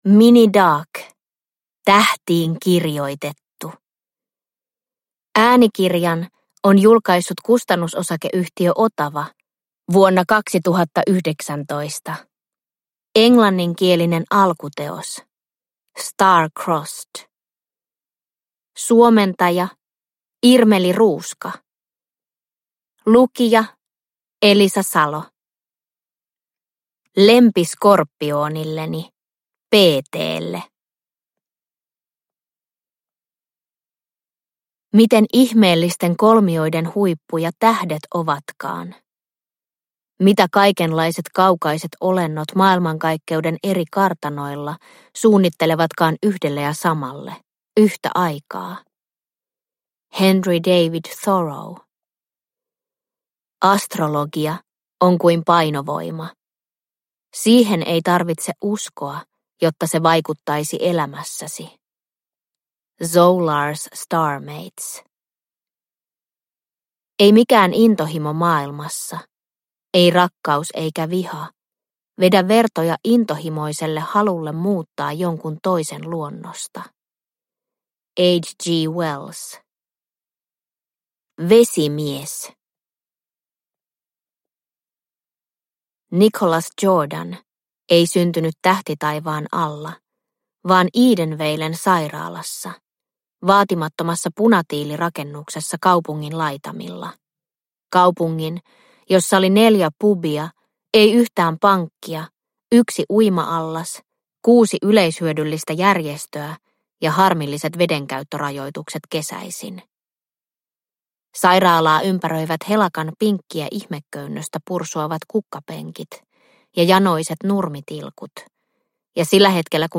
Tähtiin kirjoitettu – Ljudbok – Laddas ner